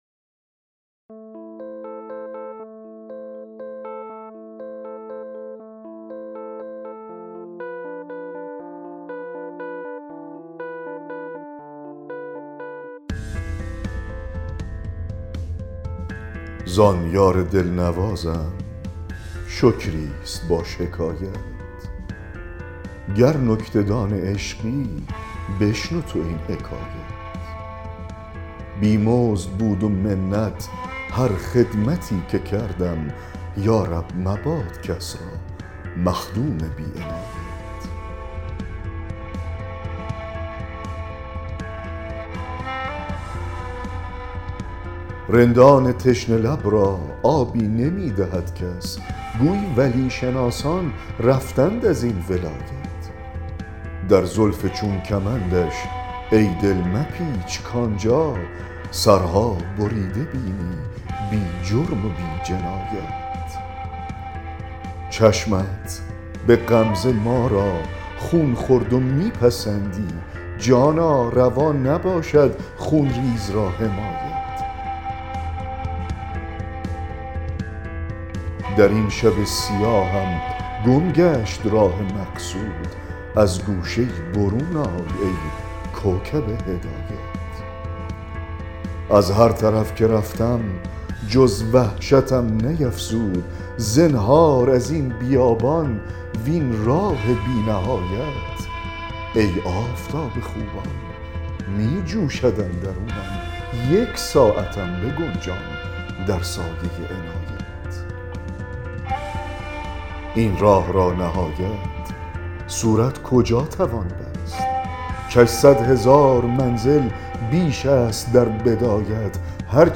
دکلمه غزل 94 حافظ
دکلمه-غزل-94-حافظ-زان-یار-دلنوازم-شکریست-با-شکایت.mp3